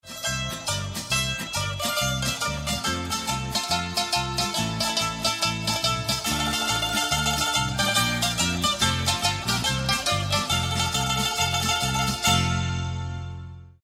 PLAY Musica Tradicional ITÁLIA